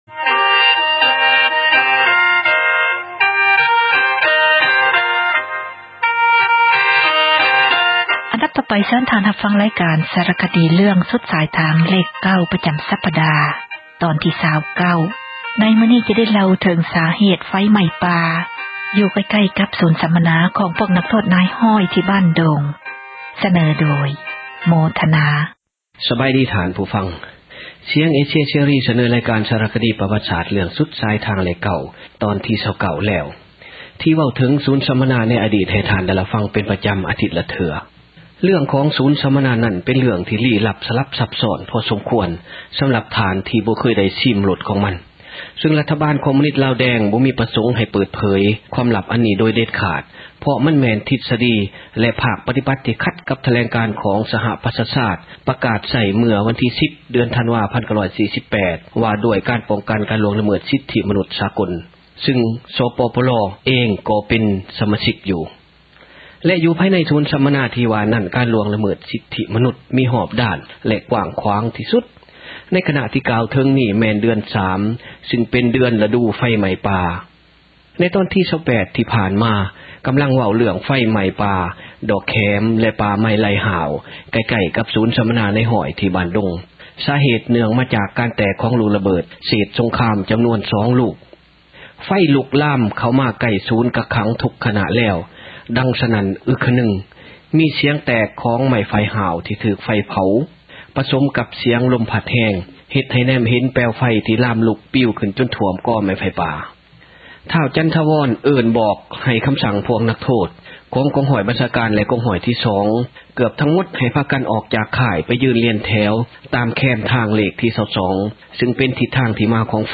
ຣາຍການ ສາຣະຄະດີ ເຣື້ອງ ”ສຸດສາຍທາງເລຂ 9” ປະຈຳສັປດາ ຕອນທີ 29. ໃນມື້ນີ້ ຈະໄດ້ເລົ່າເຖິງ ສາເຫດໄຟໄຫມ້ປ່າ ຢູ່ໃກ້ໆກັບ ສູນສັມມະນາ ຂອງພວກນັກໂທດ ນາຍຮ້ອຍ ທີ່ບ້ານດົງ. ສເນີໂດຍ